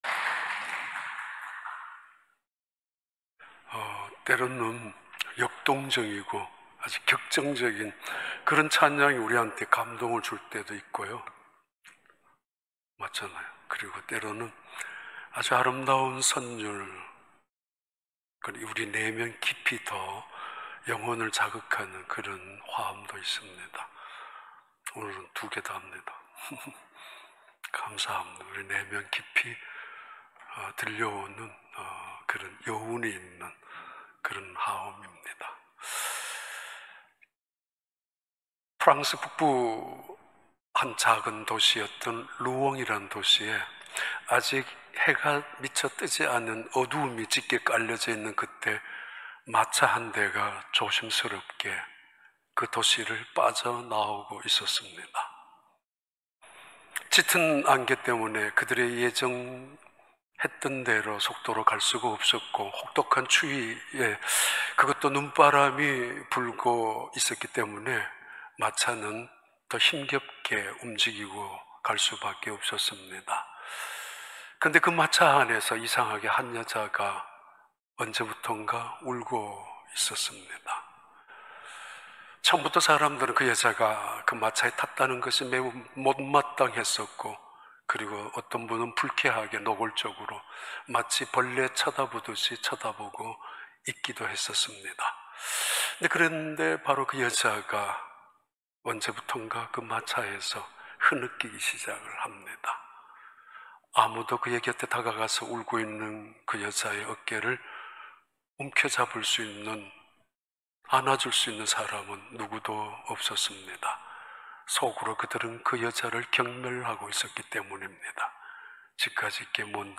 2021년 6월 20일 주일 4부 예배